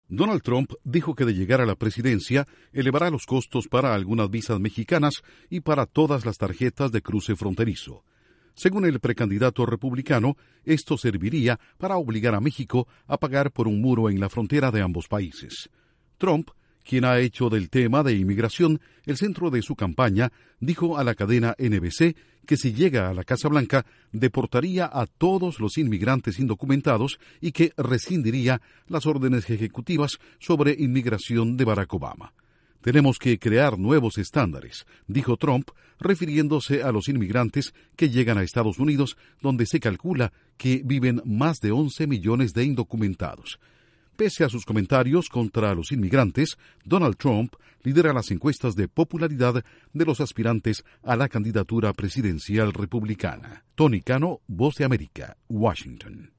En una entrevista con la cadena NBC, Donald Trump dice que de llegar a la presidencia deportaría a los indocumentados. Informa desde los estudios de la Voz de América en Washington